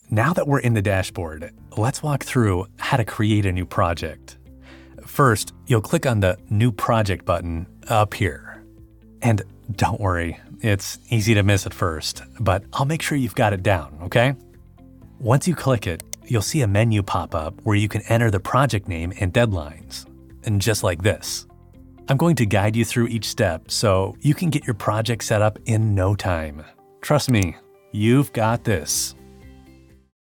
ELEARNING
NARRATION
A knowledgeable and approachable guide who makes learning new technology feel effortless, breaking down complex processes with clarity and confidence.